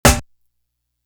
Chrome Snare.wav